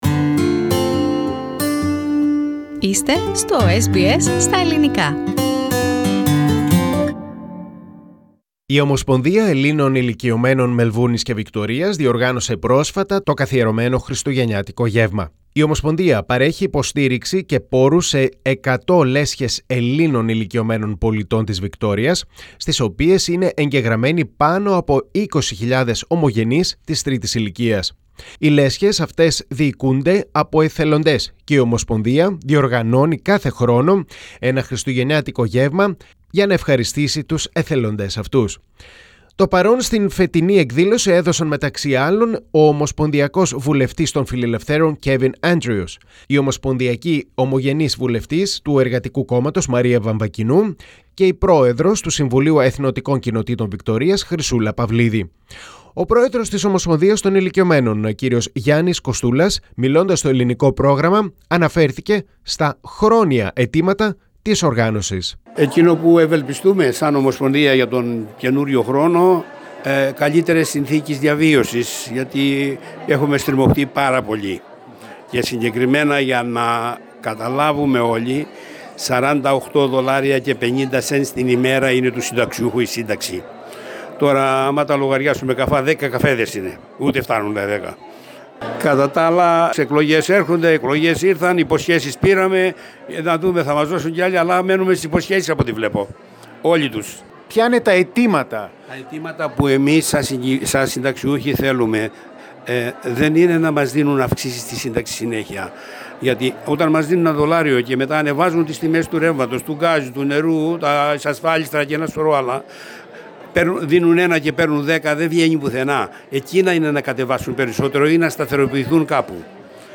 From the Christmas Celebration with the Federation of the Greek Elderly Clubs of Melbourne & Victoria Source: SBS Greek